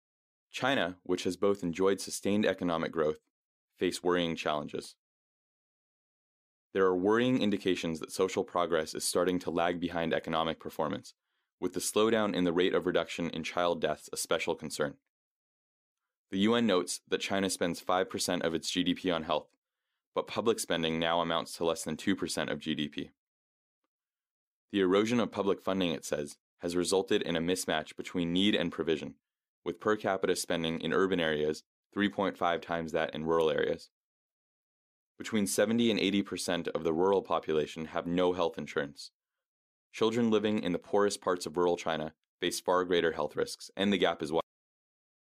Sample: You will hear a recording of a lecture. At the end of the recording the last word or group of words has been replaced by a beep.